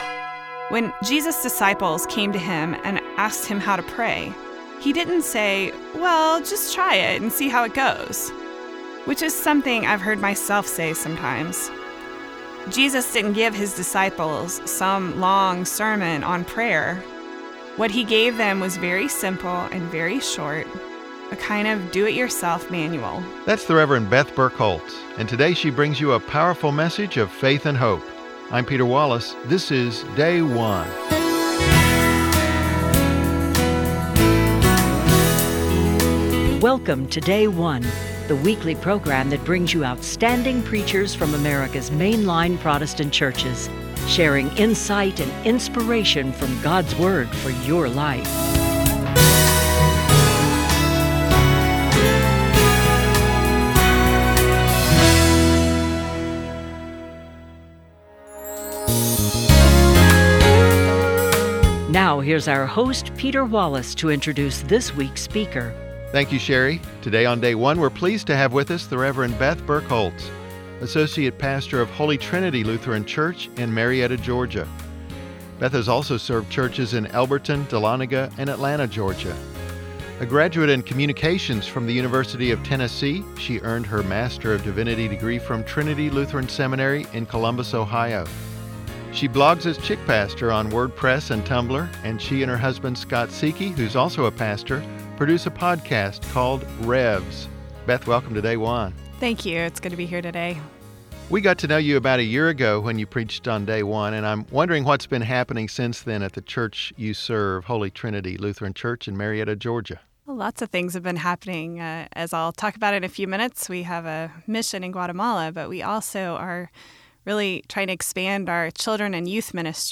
Evangelical Lutheran Church in America 10th Sunday after Pentecost - Year C Luke 11:1-13